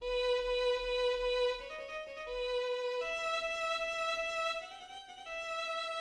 The second movement opens with a serene piano solo in E major with a lyrical melodic line.
2nd theme (E minor)